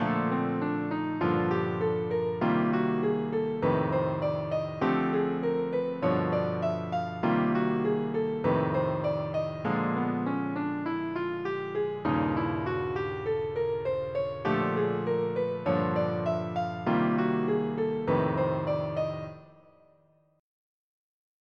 diminished scale over rhythm changes
rhythm-changes-diminished-1.mp3